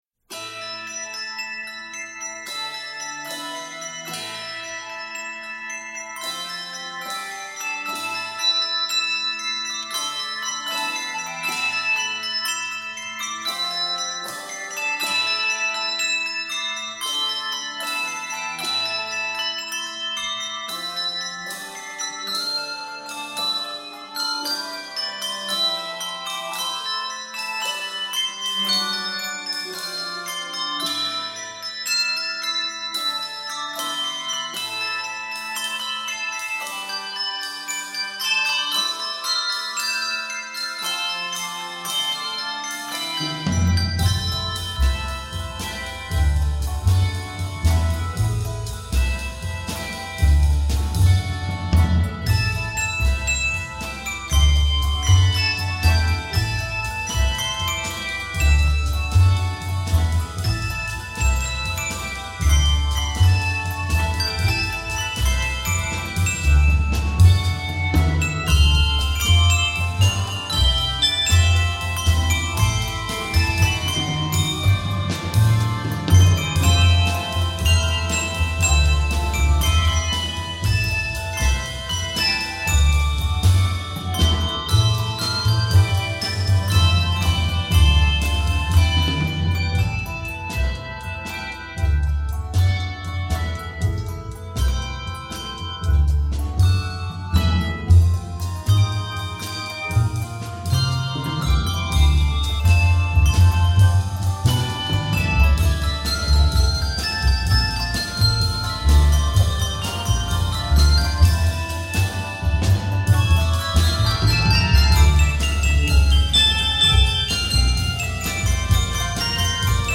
handbells
Set in the keys of A minor and D minor